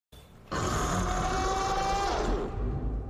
Bazelgeuse Scream